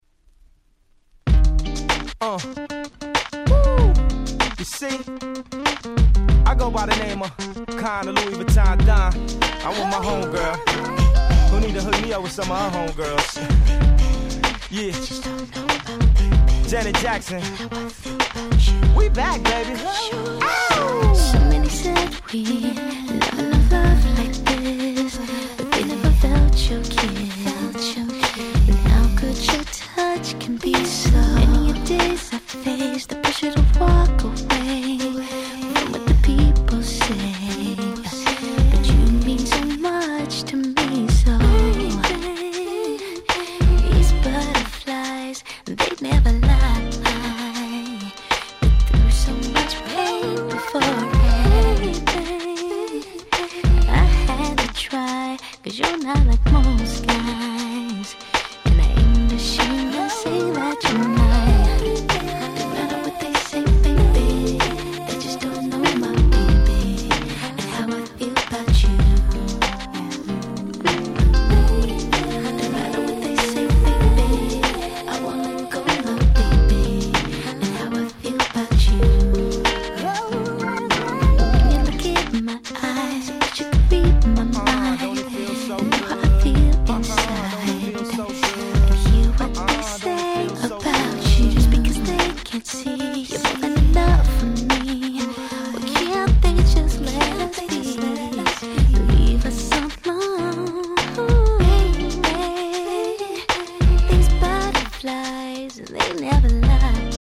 話はそれましたが、本作も所々Neo Soul風味を感じさせる良曲がちらほら。